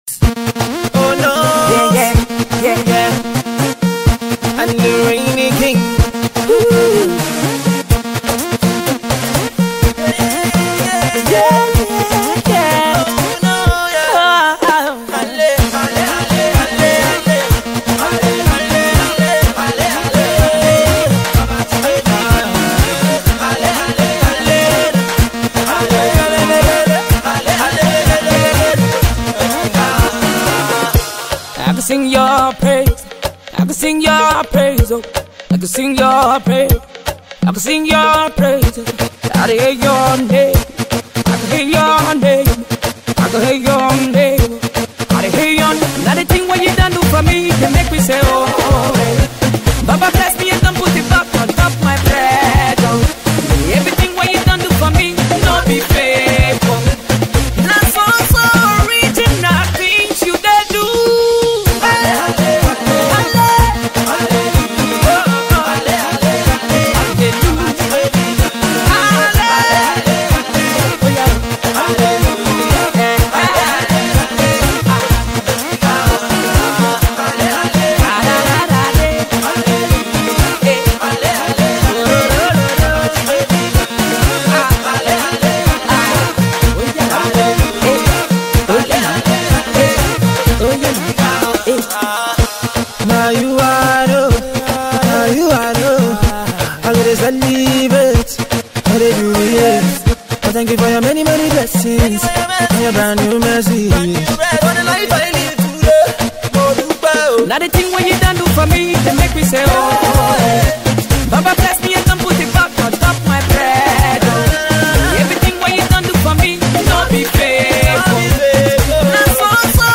February 6, 2025 Publisher 01 Gospel 0